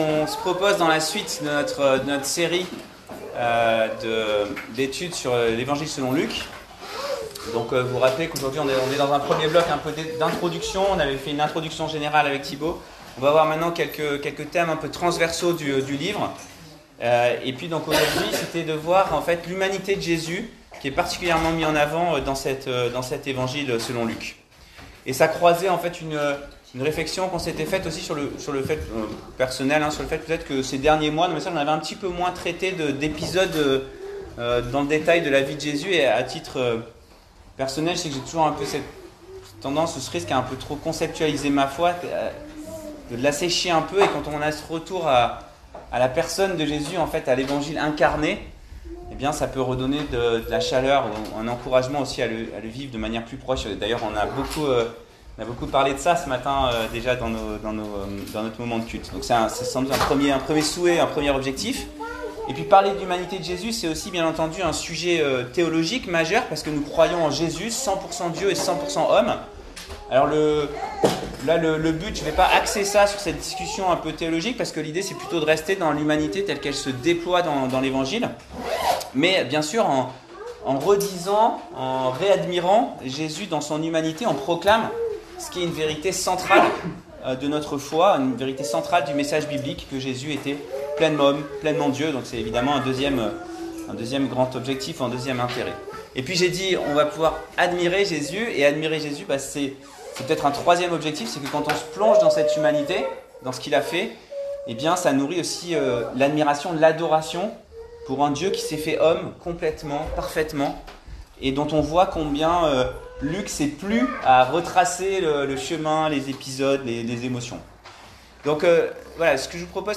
Prédications
Vous trouverez ci-dessous une sélection de prédications données lors du temps d’enseignement le dimanche matin.